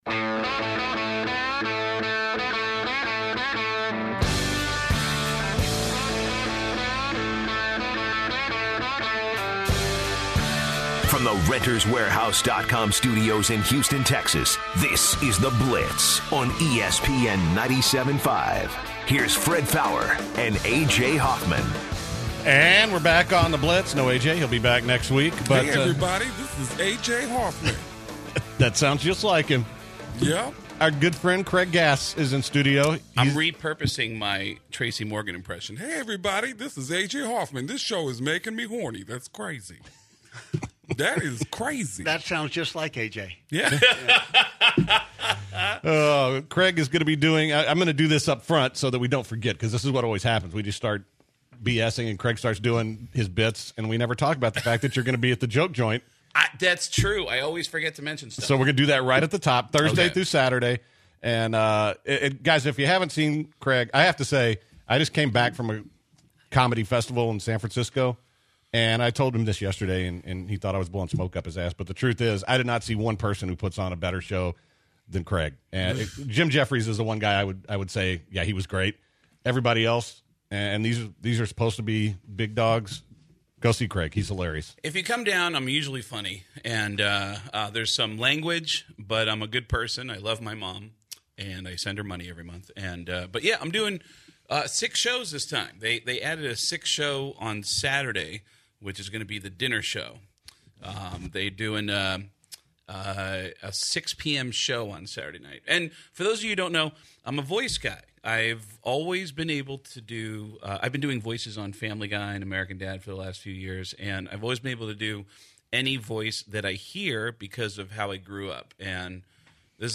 Craig Gass does impressions and tells some of his story.